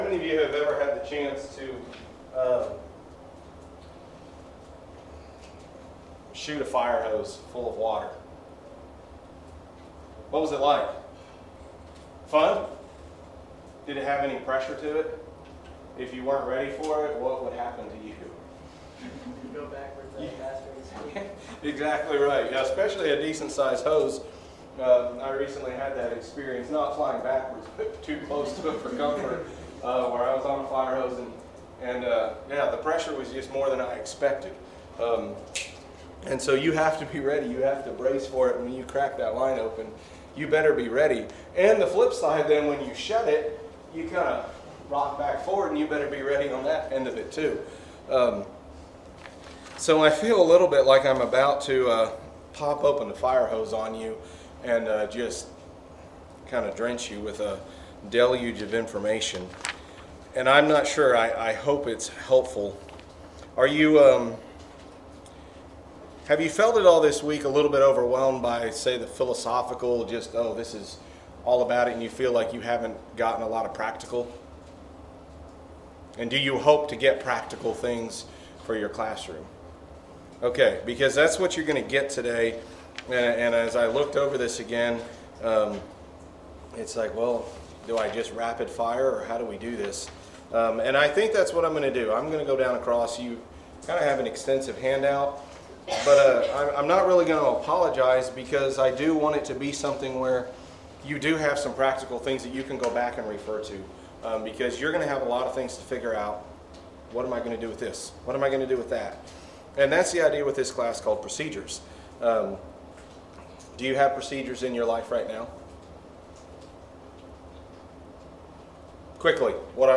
2025 Midwest Teachers Week 2025 Recordings Procedures Audio 00:00